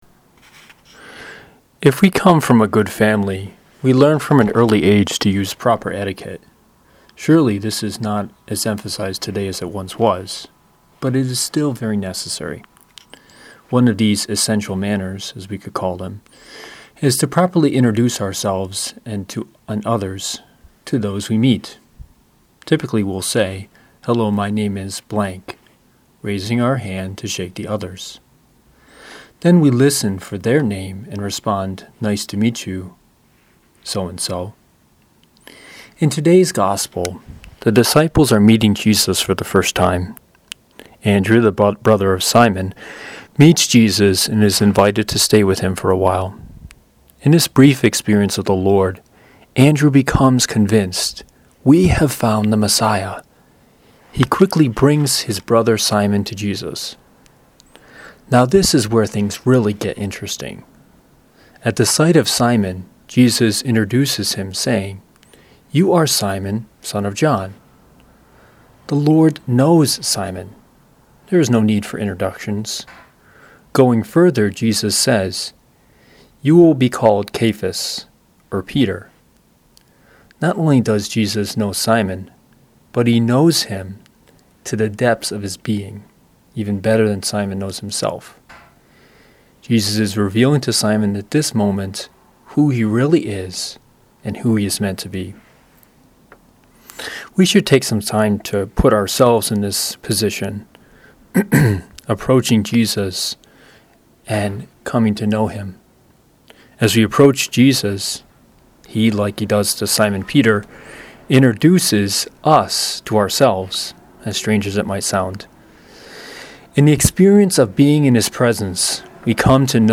- Great Catholic Homilies
Second Sunday in Ordinary Time